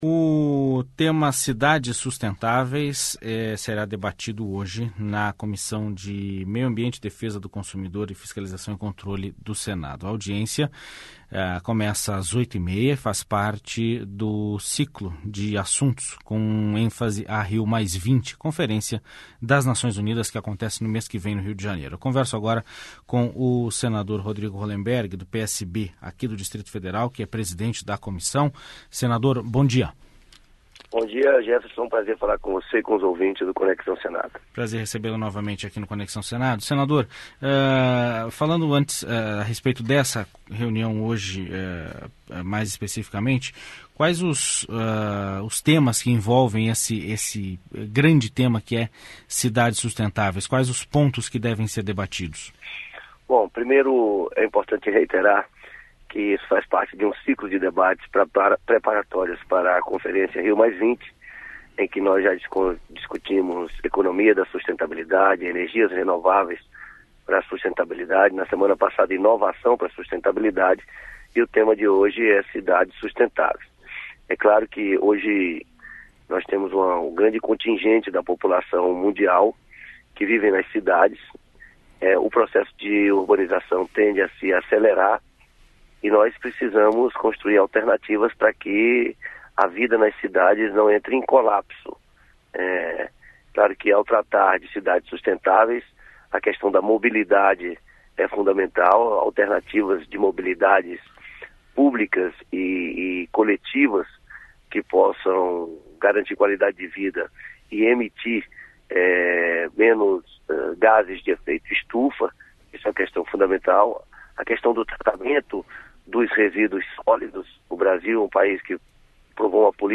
Entrevista com o senador Rodrigo Rollemberg (PSB-DF), presidente da Comissão de Meio Ambiente.